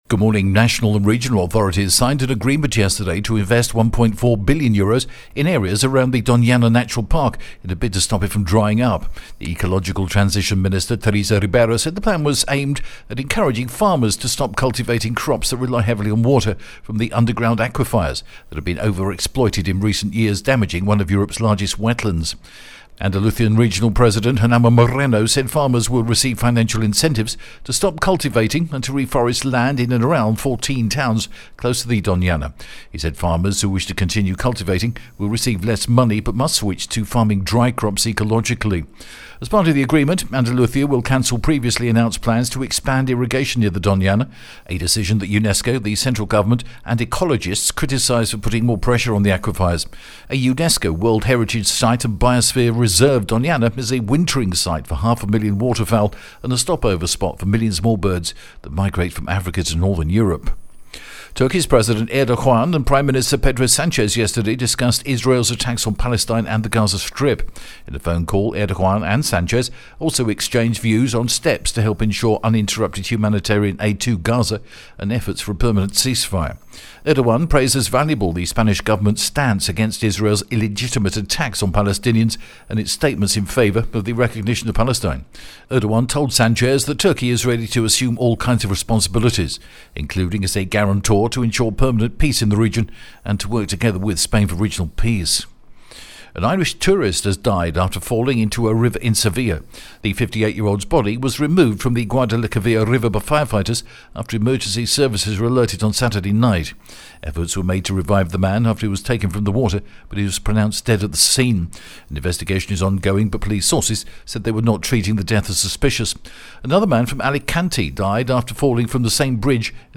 The latest Spanish news headlines in English: 28th November 2023